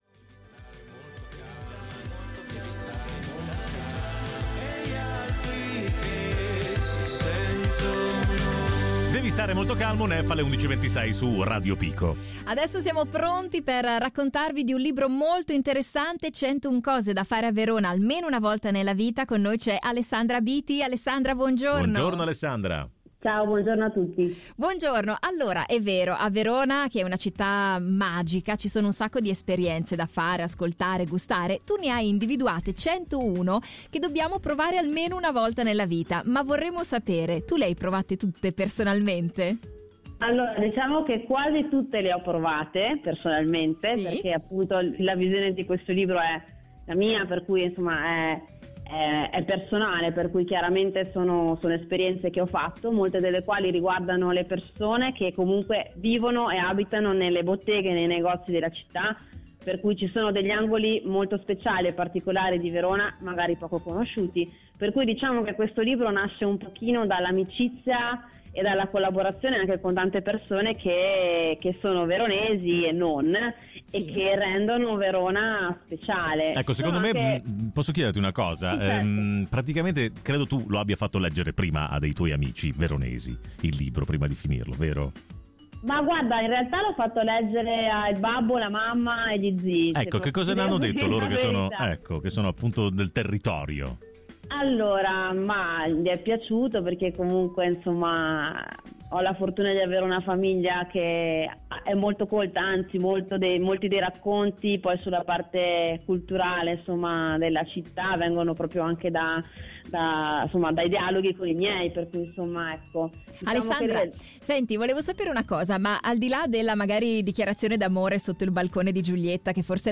Abbiamo intervistato